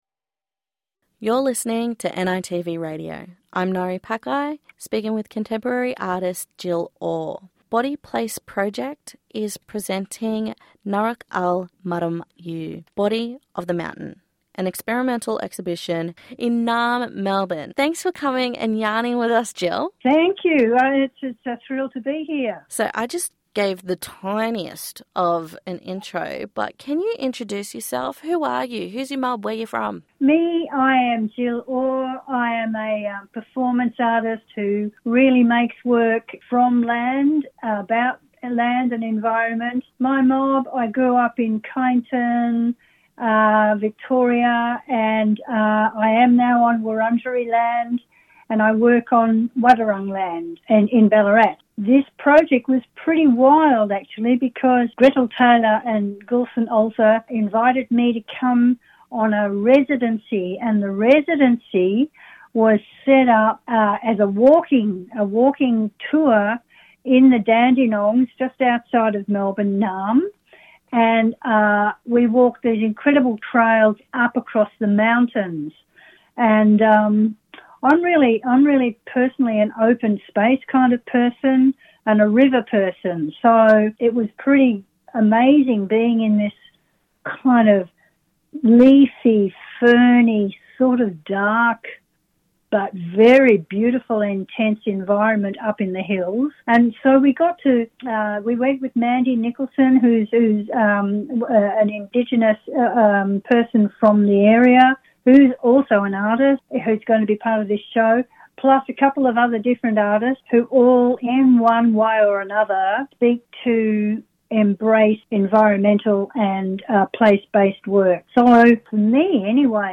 yarns with multi-diciplinary Aboriginal artist